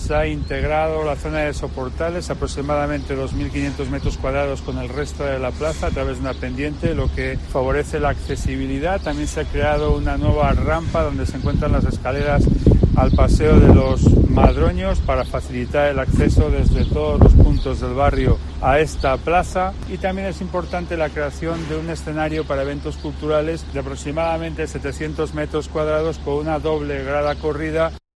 El alcade de Salamanca, señala las novedades más importantes